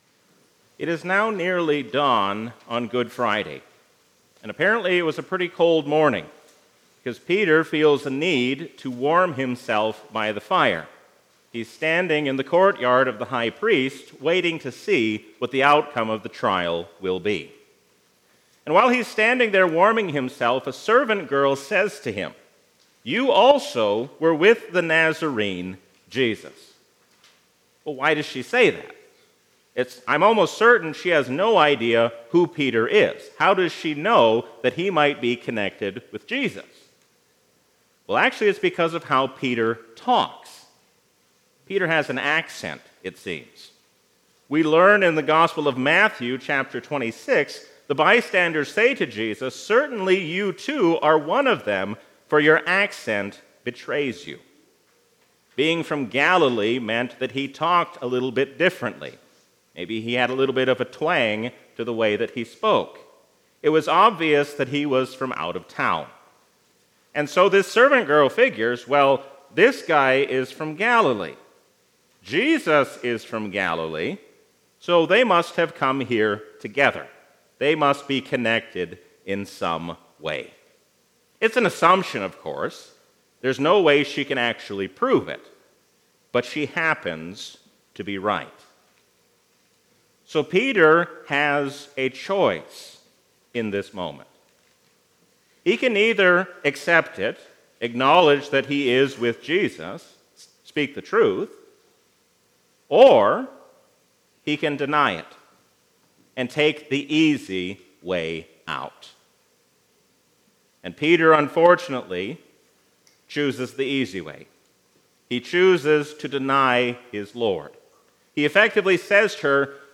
A sermon from the season "Trinity 2024." Let us not be double minded, but single minded in our devotion toward God.